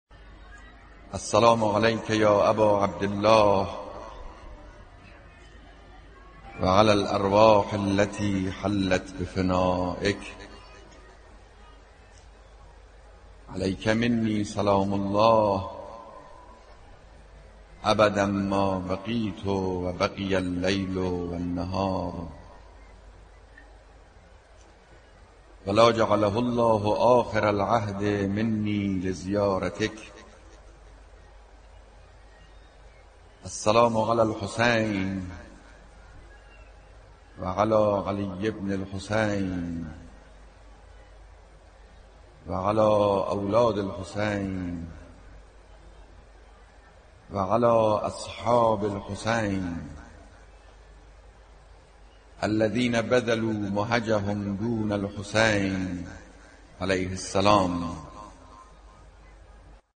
السلام علیک یا اباعبدالله (با صدای رهبر انقلاب)